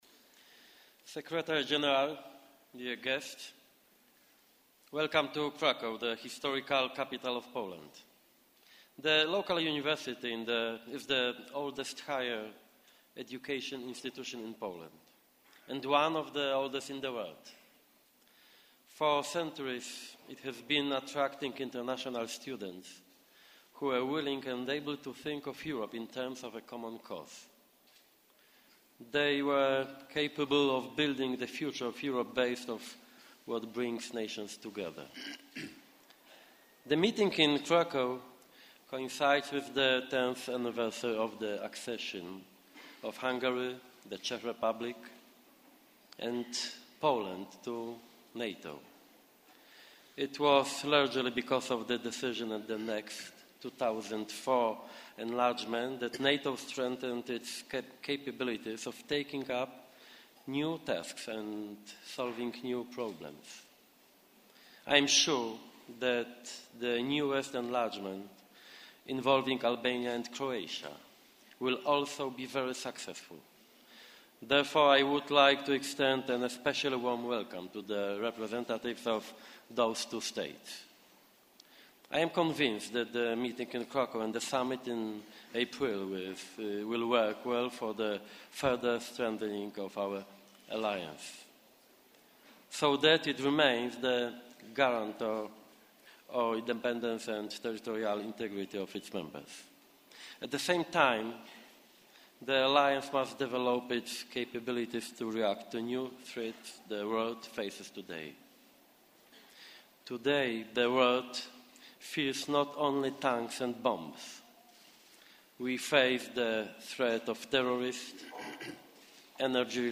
Remarks by Donald Tusk, Prime Minister of Poland at the Cultural event at the National museum - Cracow, Poland